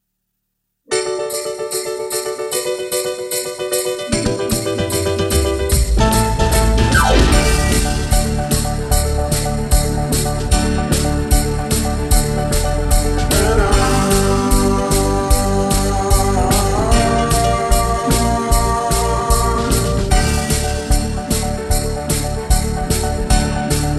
Five Semitones Down Pop (1990s) 3:50 Buy £1.50